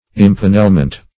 Impanelment \Im*pan"el*ment\, n.